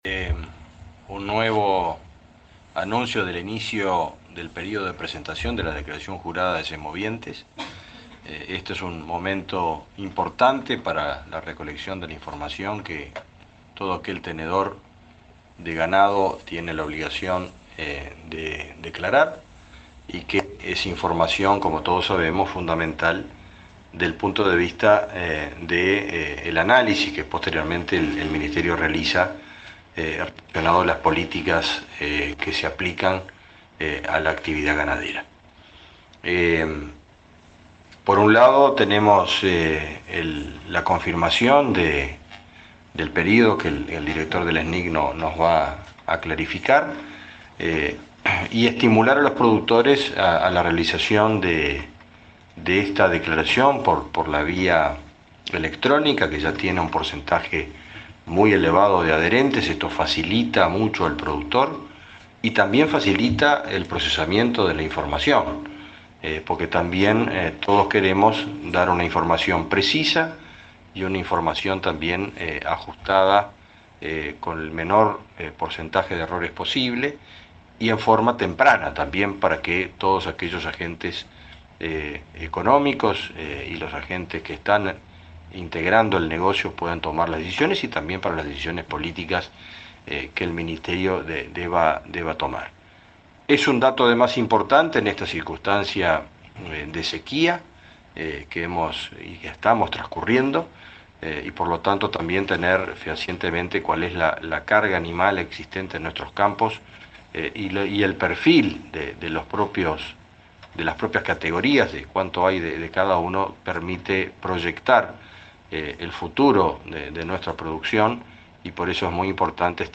Palabras de autoridades del Ministerio de Ganadería
El ministro de Ganadería, Fernando Mattos, y el director del Sistema Nacional de Información Ganadera (SNIG), Gabriel Osorio, informaron a la prensa